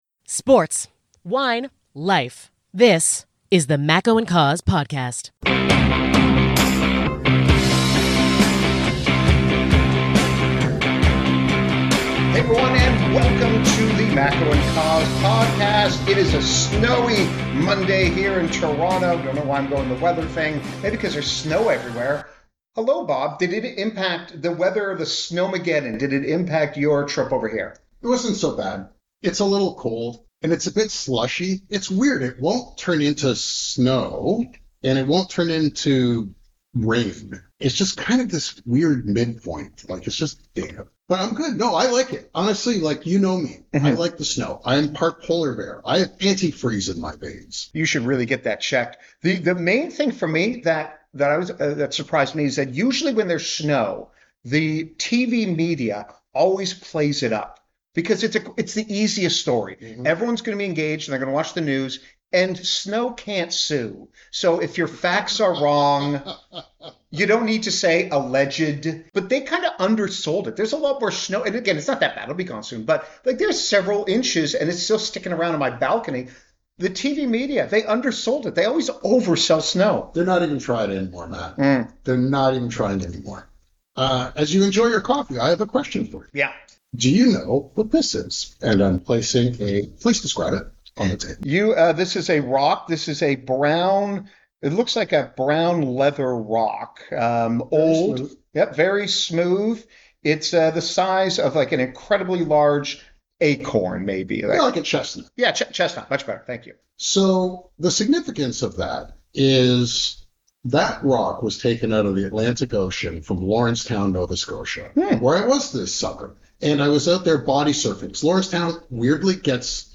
NOTE: Some audio issues on this one.